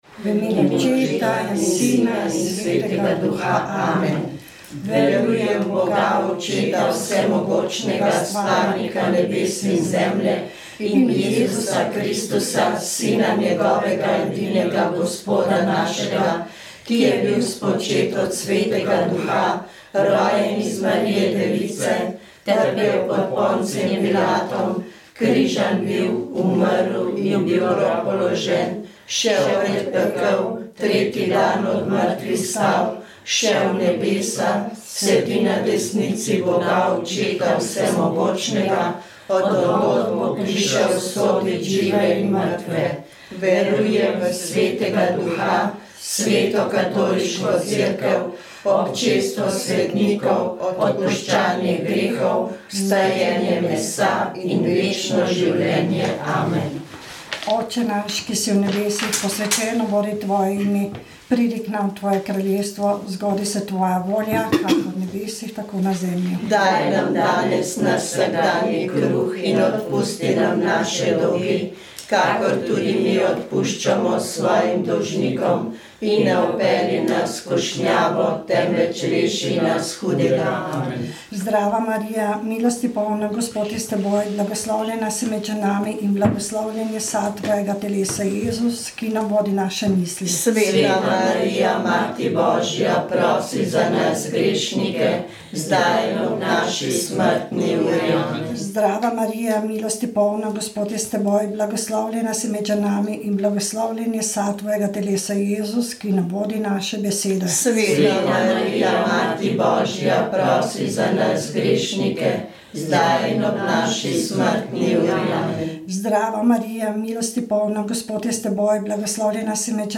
Rožni venec
Molili so člani Karitas iz župnije Domžale.